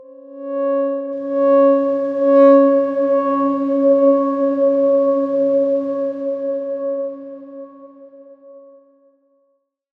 X_Darkswarm-C#4-pp.wav